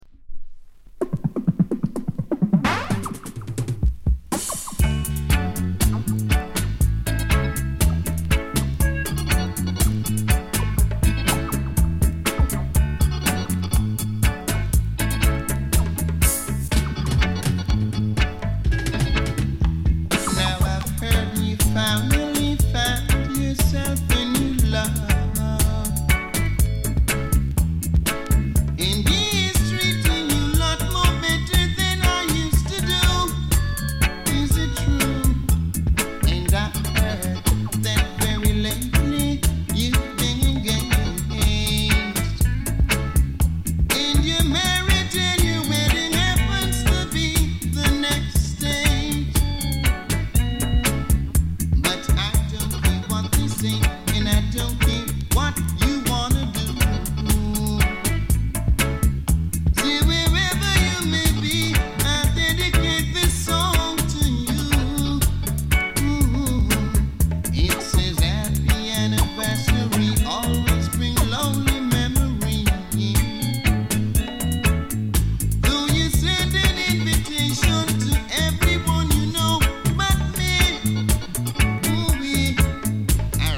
高音質